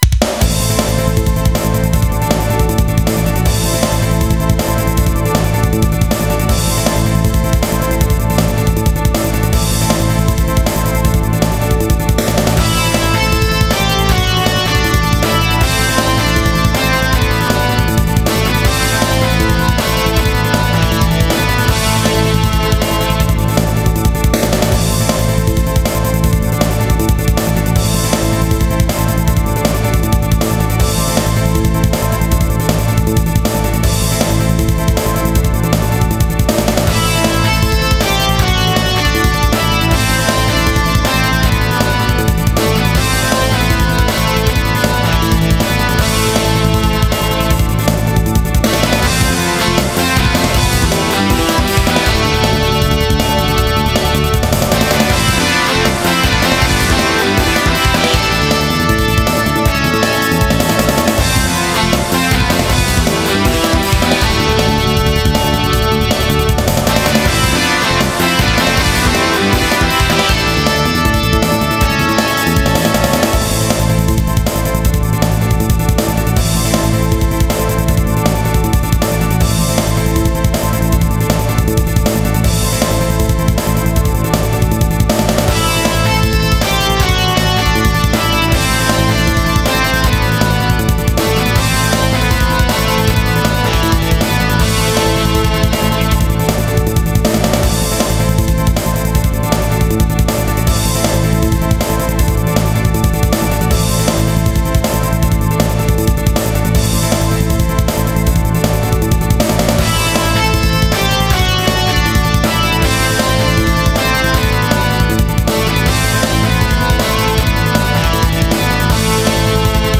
ノリの良いエレキギターの効いた曲です。